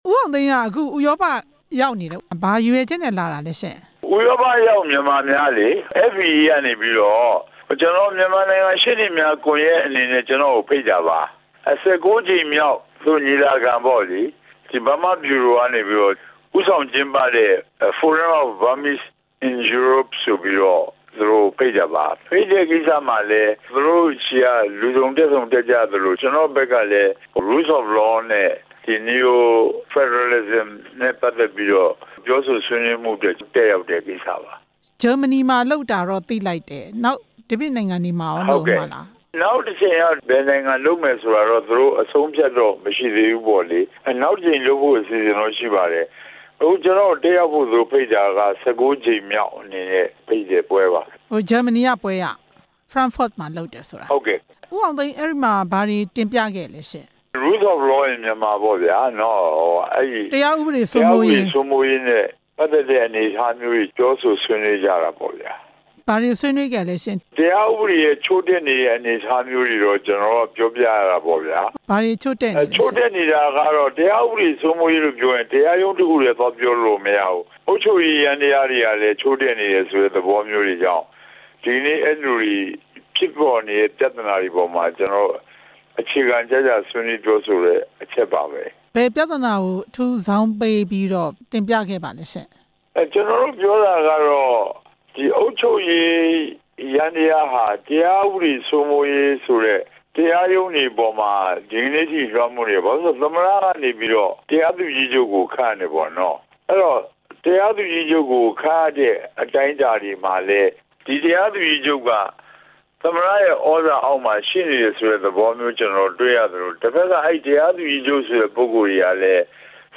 ဥရောပရောက် မြန်မာများ ညီလာခံ မေးမြန်းချက်